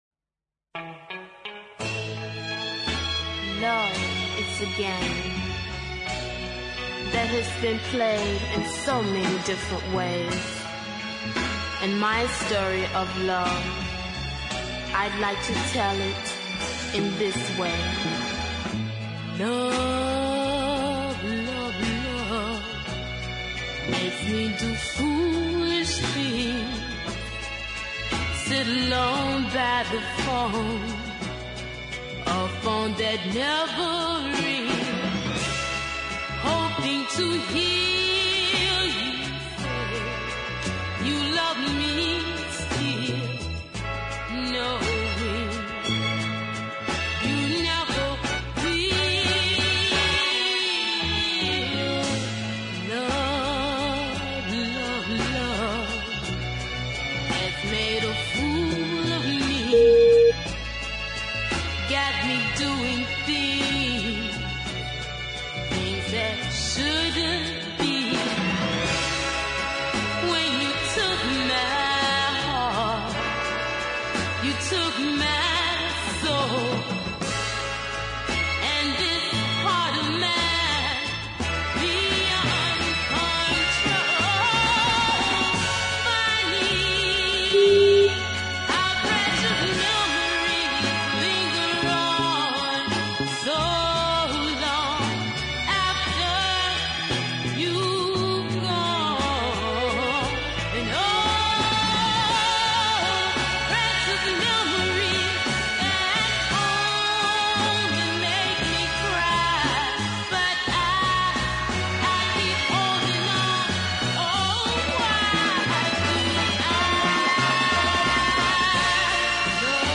super deep ballad